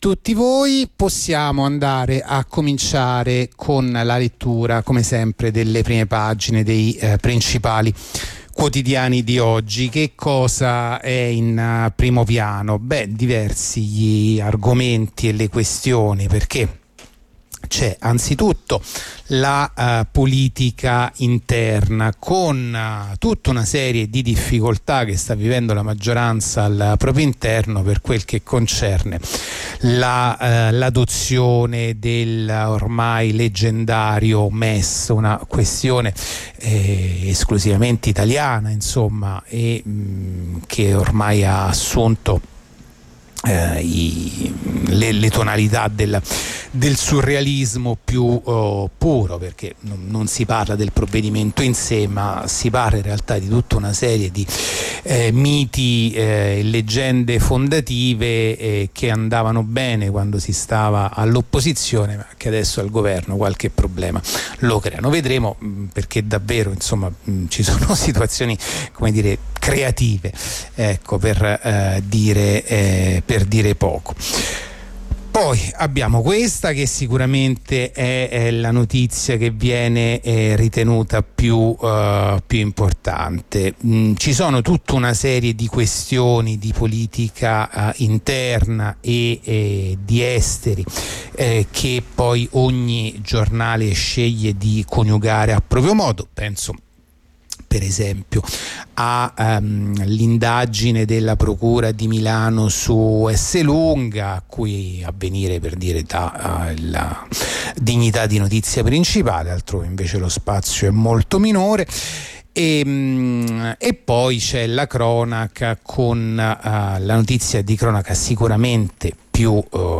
La rassegna stampa di radio onda rossa andata in onda venerdì 23 giugno 2023